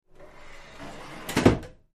Dishwasher; Door 1; Dishwasher Door Sliding Into Close Position. Close Perspective Kitchen, Restaurant.